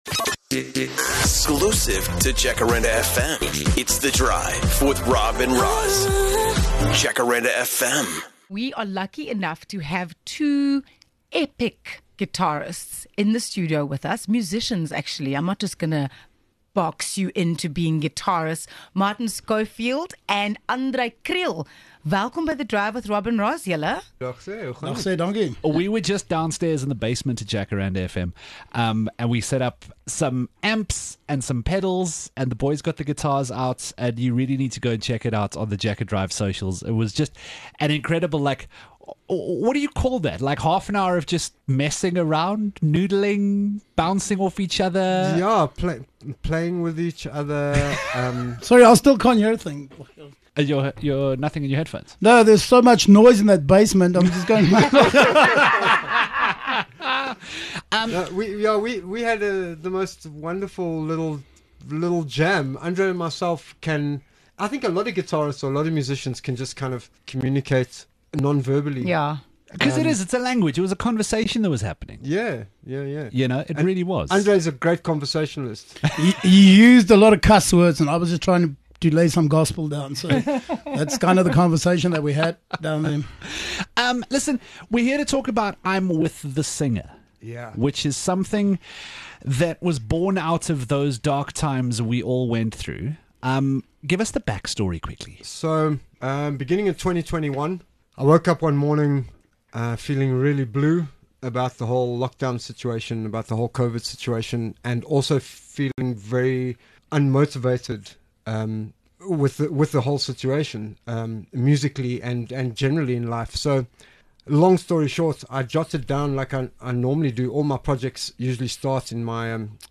These two joined us in the studio to talk about rock n roll and an exciting new project they have called, 'I'm With The Singer'.